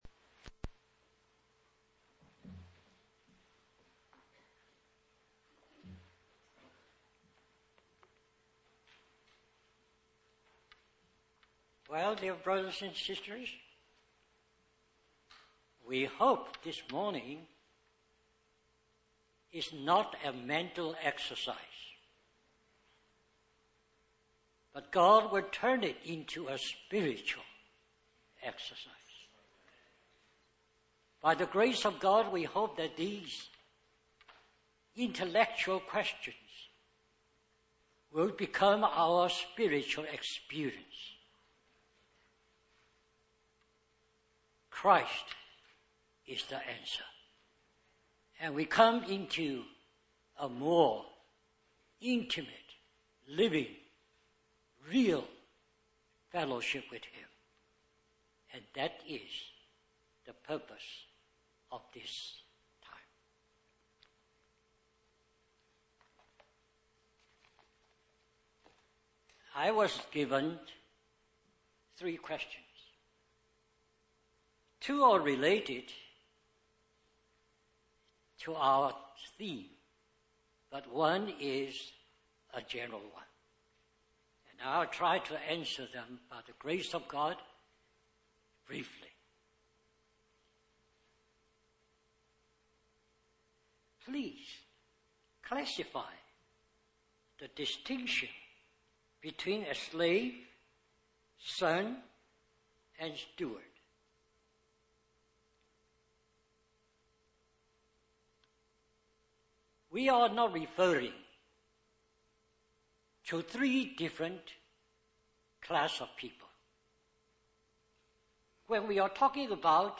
Harvey Cedars Conference
Question and Answer